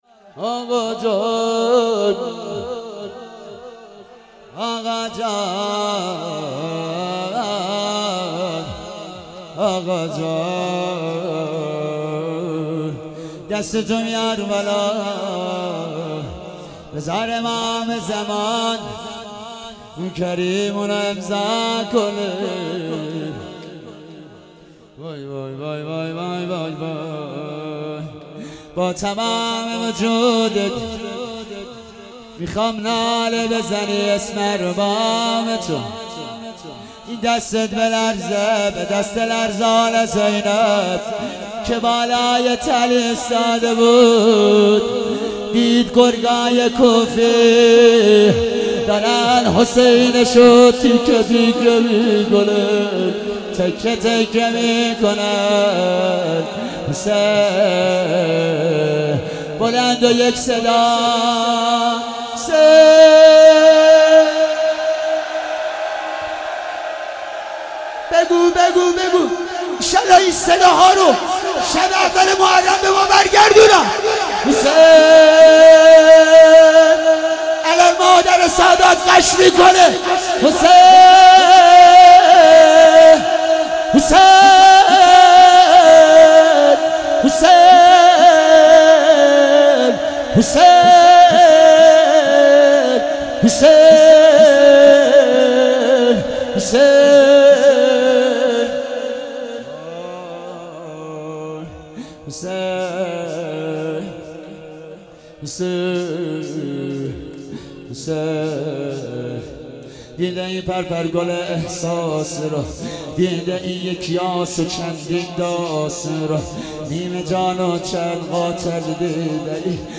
هفتگی 29 اردیبهشت_روضه پایانی